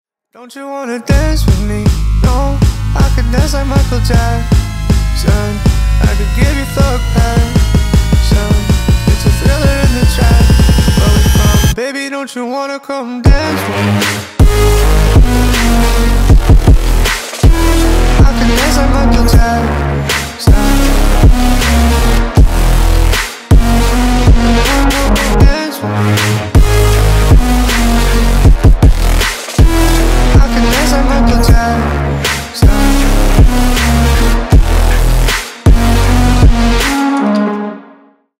бесплатный рингтон в виде самого яркого фрагмента из песни
Ремикс # Поп Музыка
громкие